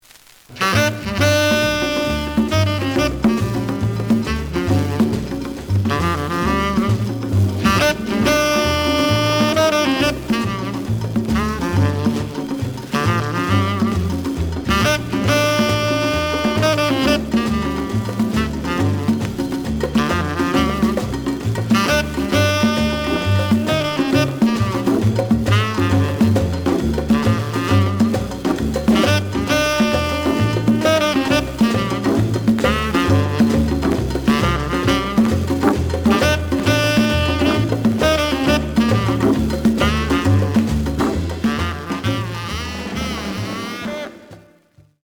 The audio sample is recorded from the actual item.
●Genre: Modern Jazz
Slight noise on both sides.